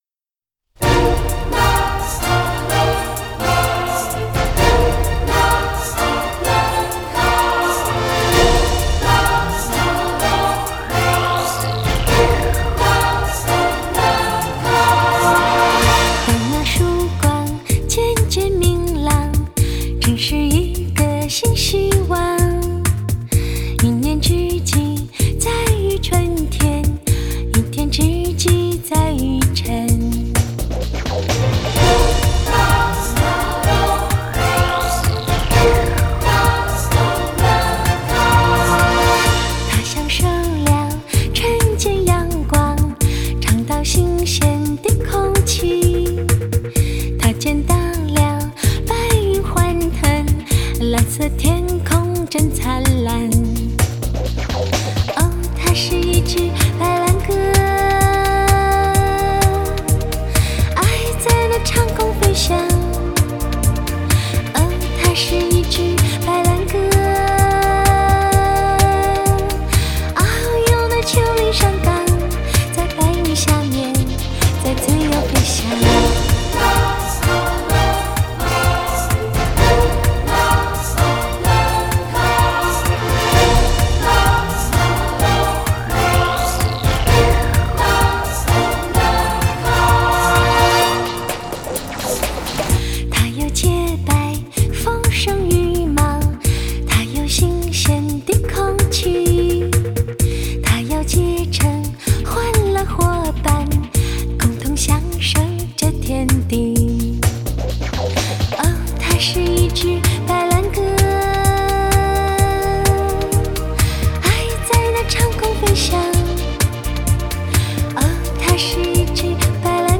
这是一张集合了多种音乐元素的唱片，首先唱片的选曲相当创新，选取的是世界各地的著名改编民歌，这类型的唱片的确让人眼前一亮。
典雅悠柔、独具一格的唱腔
以现代化的编曲手法和新颖的唱腔，赋予这些歌曲新的魅力，让人耳目一新。